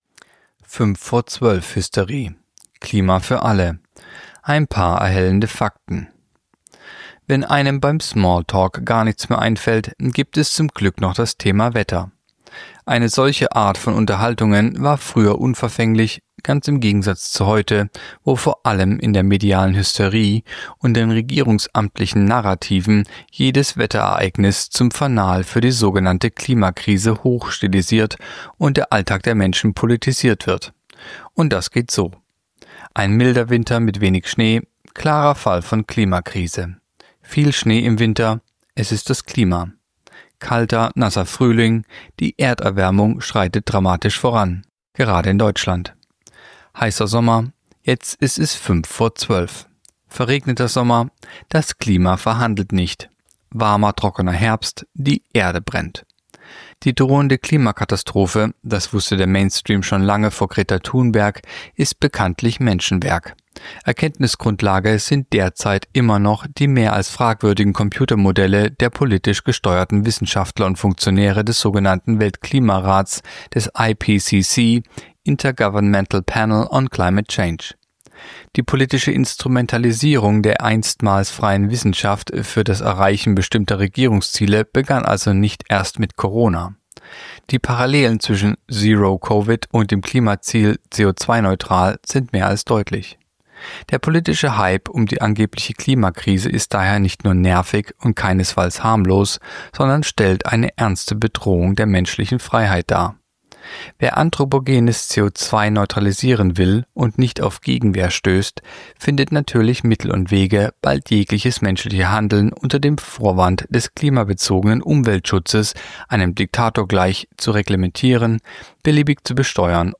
(Sprecher)